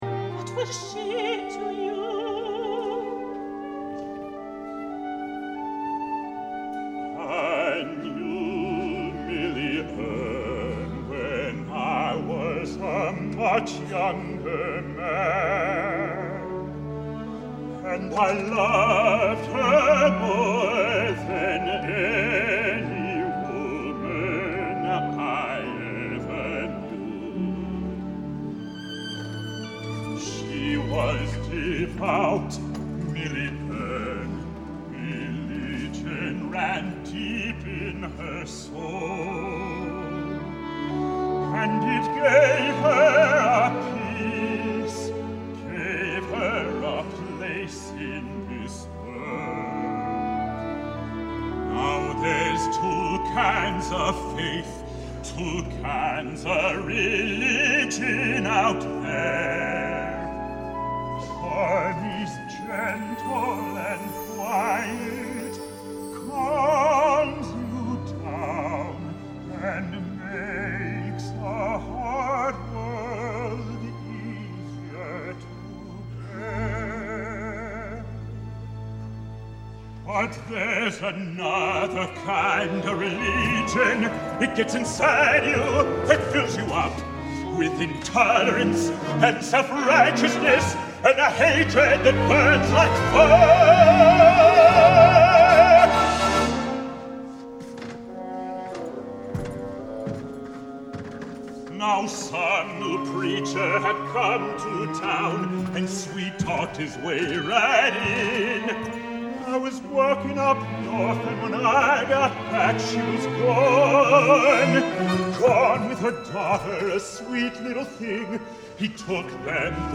Voicing: Baritone/Bass Voice